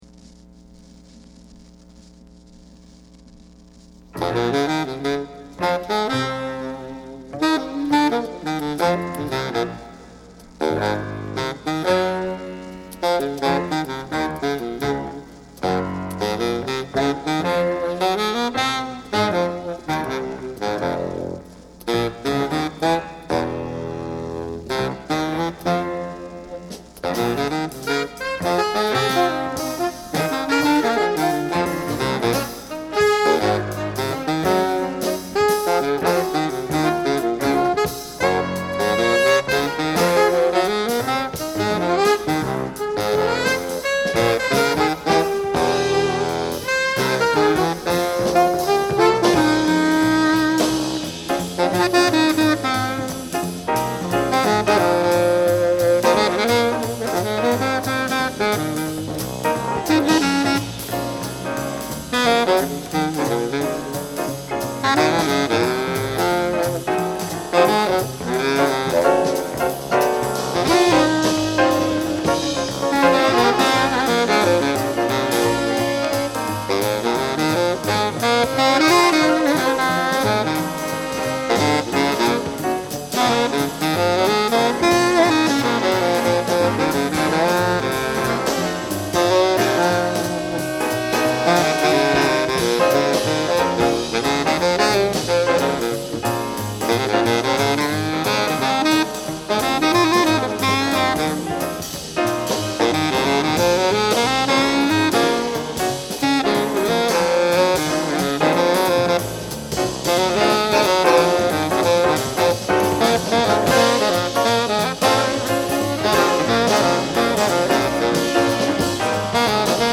Genre: Big Band Jazz / Jazz Fusion
1978年3月、ニューヨークRCAスタジオ録音。
Side Bで最もグルーヴのある一曲。